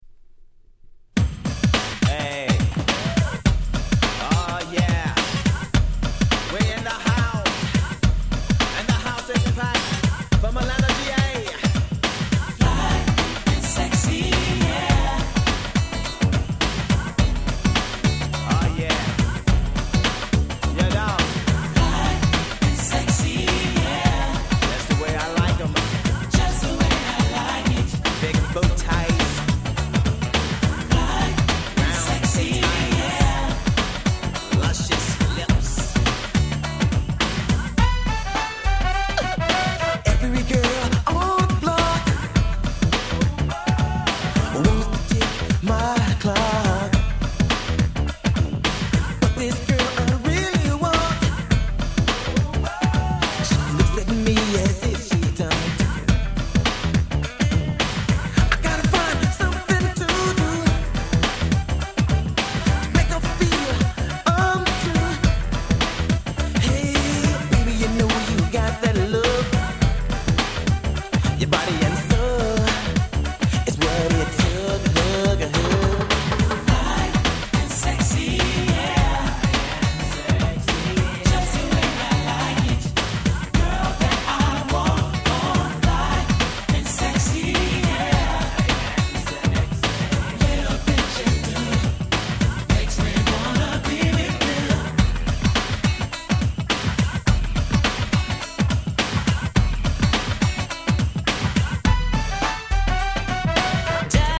92' 人気New Jack Swing！！